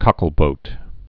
(kŏkəl-bōt)